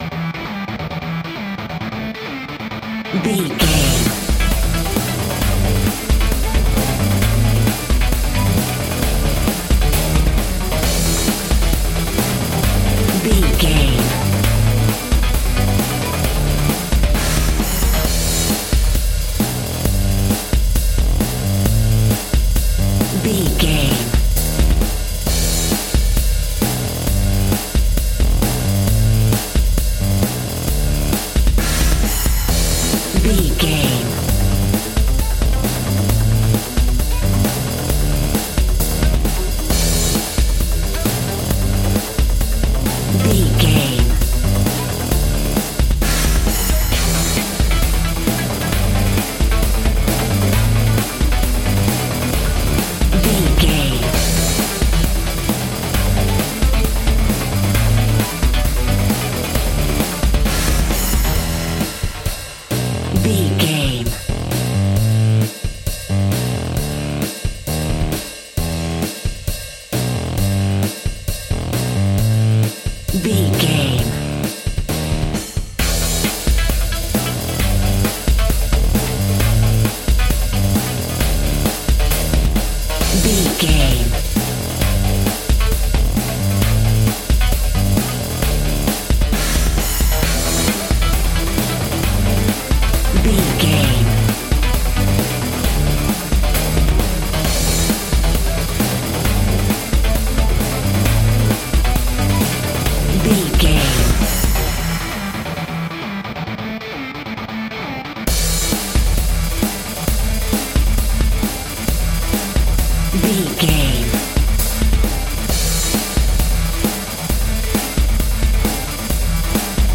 Epic / Action
Fast paced
Aeolian/Minor
Fast
intense
high tech
futuristic
energetic
driving
repetitive
dark
synthesiser
drums
drum machine
breakbeat
power rock
synth lead
synth bass